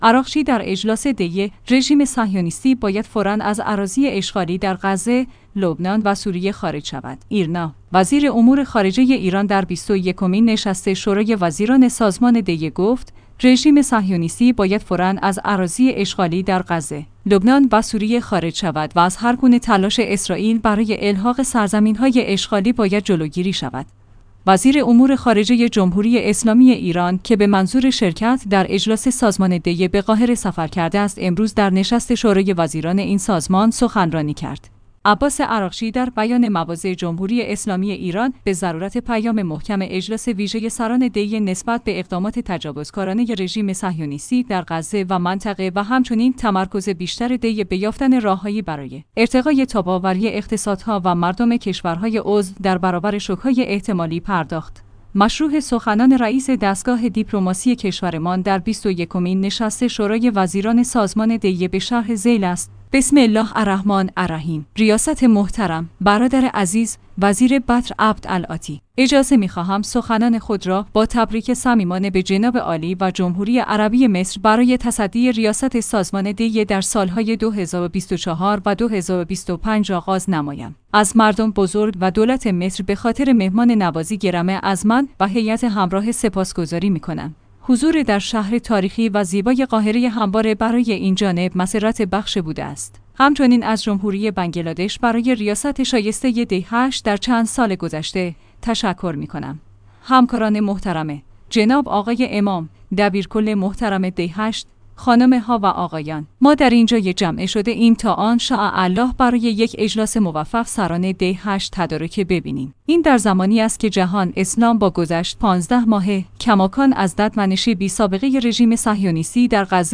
ایرنا/ وزیر امور خارجه ایران در بیست و یکمین نشست شورای وزیران سازمان دی ۸ گفت: رژیم صهیونیستی باید فوراً از اراضی اشغالی در غزه، لبنان و سوریه خارج شود و از هرگونه تلاش اسرائیل برای الحاق سرزمین‌های اشغالی باید جلوگیری شود.